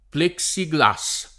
vai all'elenco alfabetico delle voci ingrandisci il carattere 100% rimpicciolisci il carattere stampa invia tramite posta elettronica codividi su Facebook plexiglas [ plek S i g l #S o pl $ k S i g la S ] s. m. — nome depositato